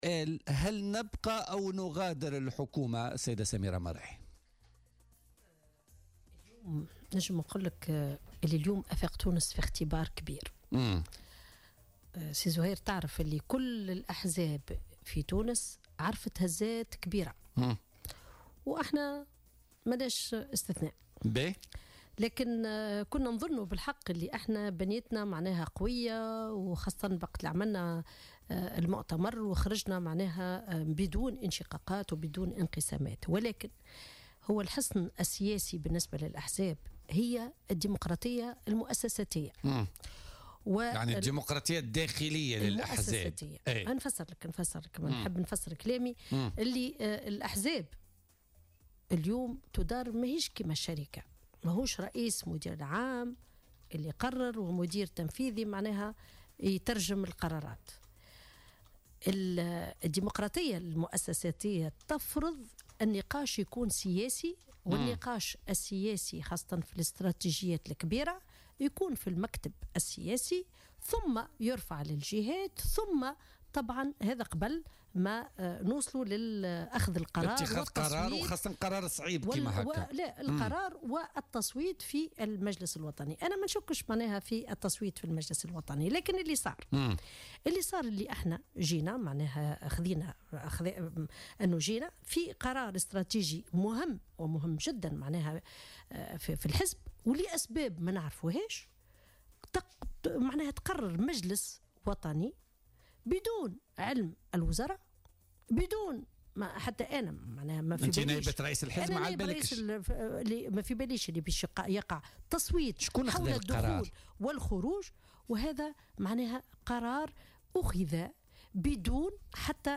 أكدت نائب رئيس حزب افاق تونس سميرة مرعي ضيفة بولتيكا اليوم الإثنين 25 ديسمبر 2017 أن افاق تونس يمر اليوم بإختبار كبير مضيفة أن كل الأحزاب عرفت هزات كبيرة وافاق ليس استثناء على حد قولها.